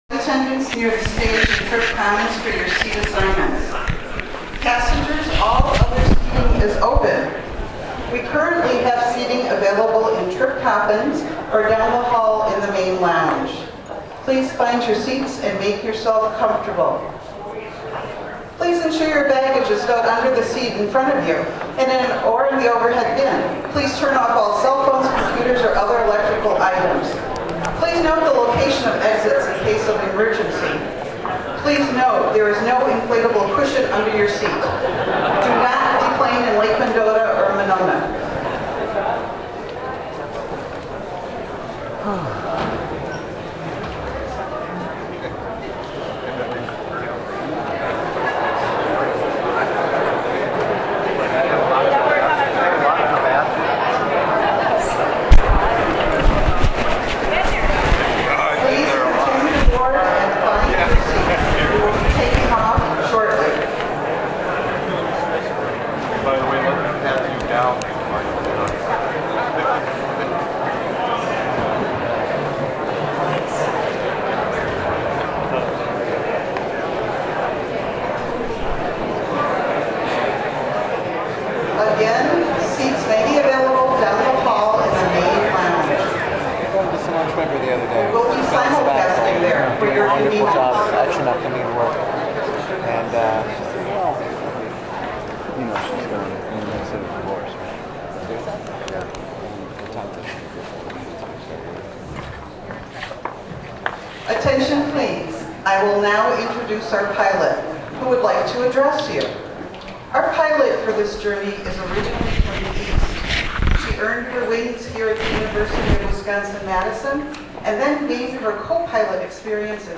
This presentation welcomes the public to the opening of the Wisconsin Institutes for Discovery scheduled later that year in December 2010.